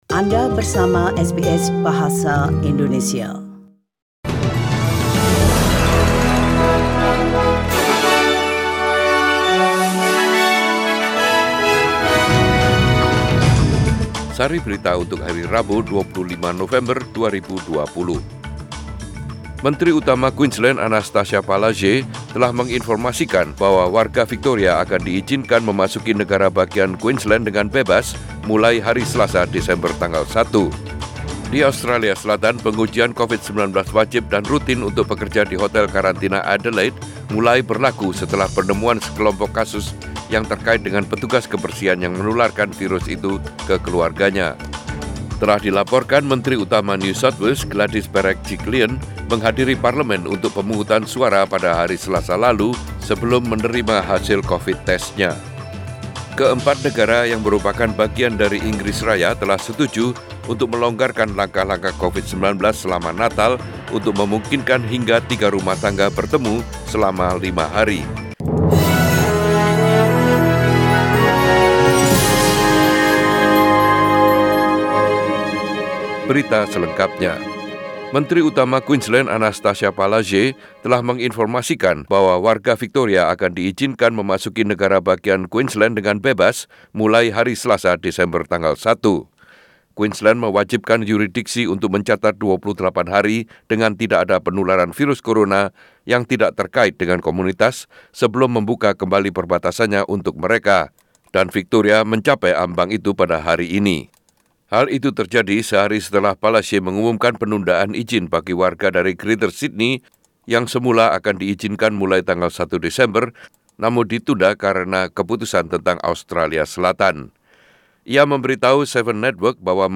SBS Radio News in Bahasa Indonesia - 25 November 2020